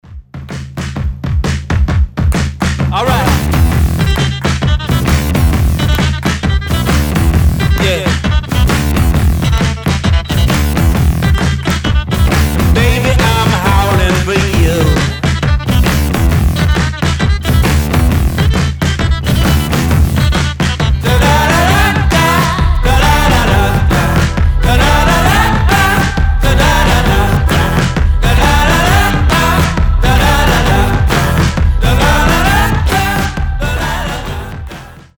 • Качество: 320, Stereo
indie rock
garage rock
Блюз-рок